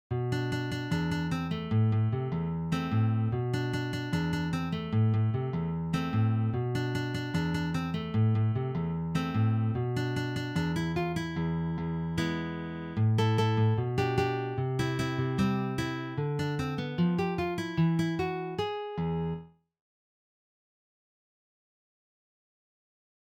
Pop/Rock/Elektronik
Sololiteratur
Gitarre (1)